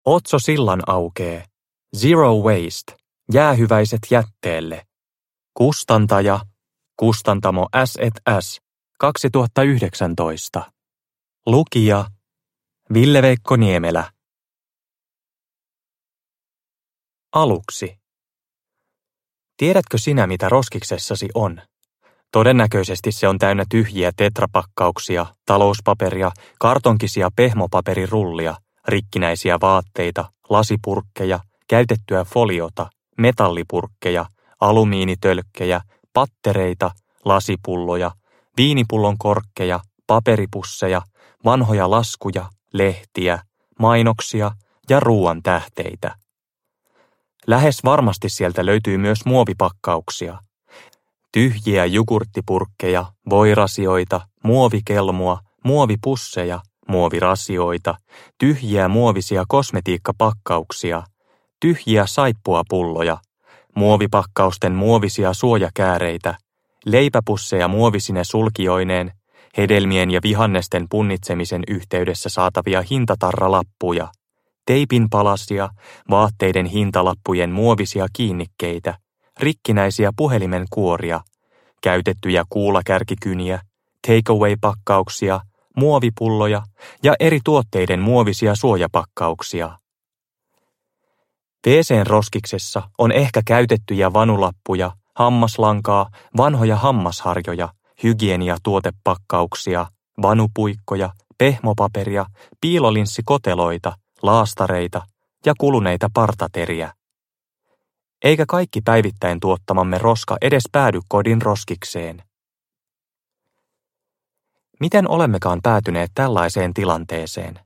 Zero Waste – Ljudbok – Laddas ner